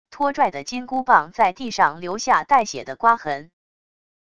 拖拽的金箍棒在地上留下带血的刮痕wav音频